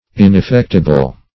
Ineffectible \In`ef*fect"i*ble\, a.
ineffectible.mp3